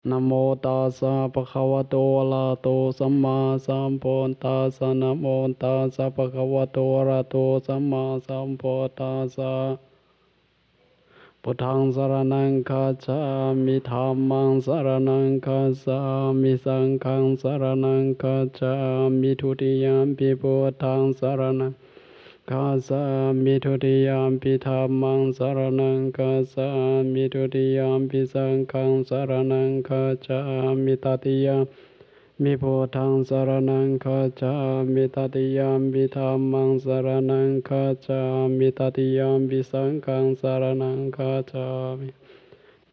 Monk chanting, Luang Prabang
Monk chanting at Luang Prabang. Stereo 44kHz 16bit.